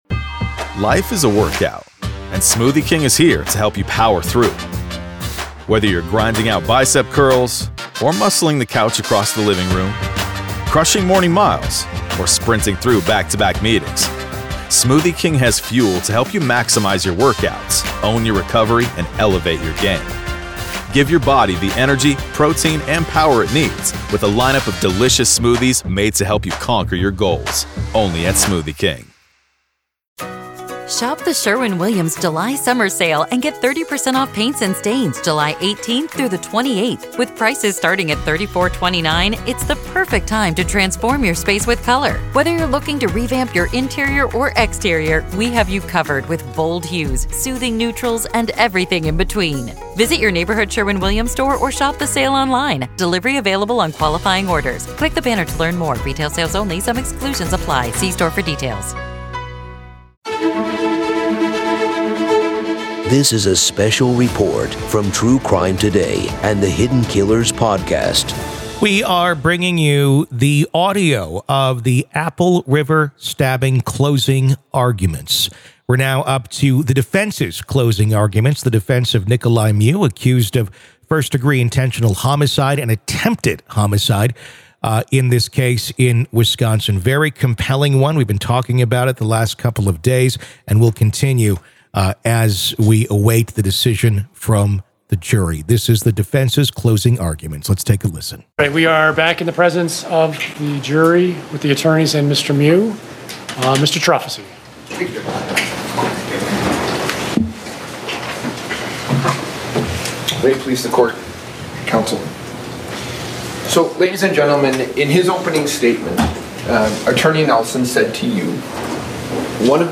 Defense Closing Arguments
This episode meticulously unfolds the harrowing events that led to this tragic incident, providing a comprehensive background that sets the stage for the episode's climax: the compelling closing arguments from both the defense and the prosecution. Through expert interviews, detailed analysis, and dramatic reconstructions, "Hidden Killers" offers a unique insight into the minds of the individuals involved, painting a vivid picture of the motives and circumstances that culminated in this shocking crime.